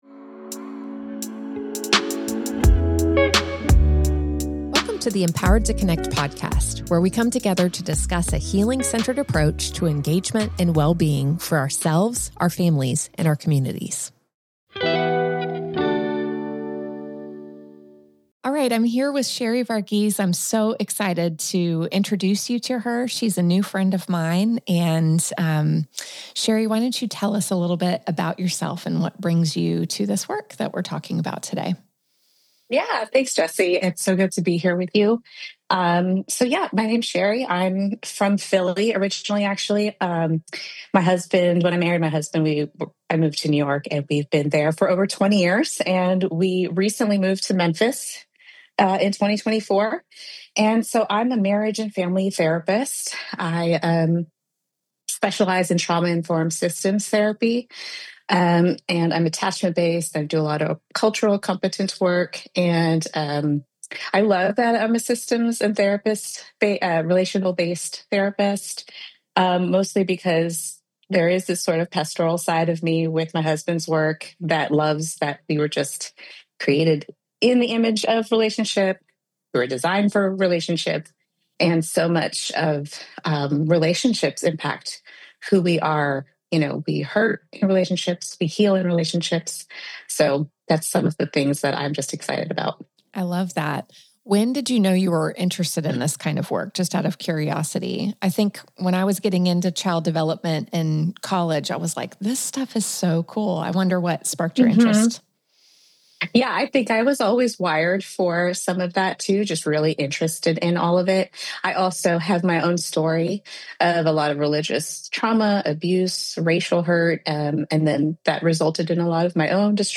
This conversation is about compassion over shame.